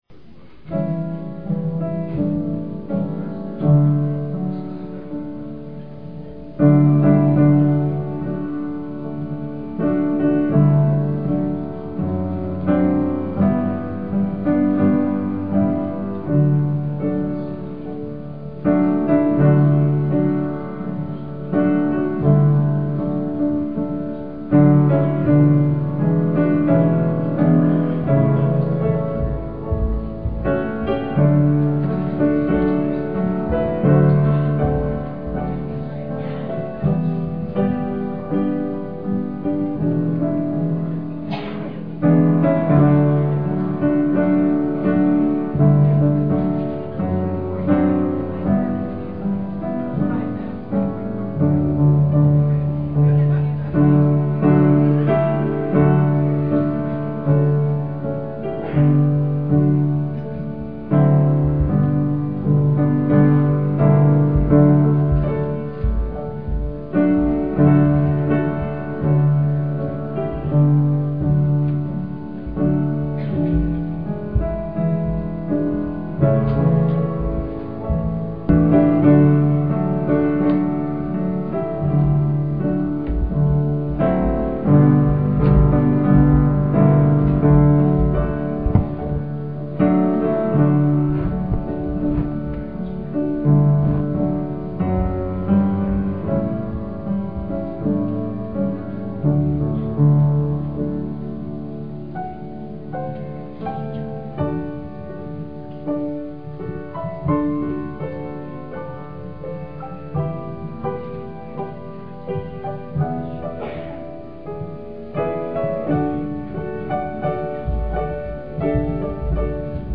PLAY Heroes of Faith, Part 1, Oct 22, 2006 Scripture: Hebrews 11:1-7. Scripture Reading
Duet
trumpet
alto saxophone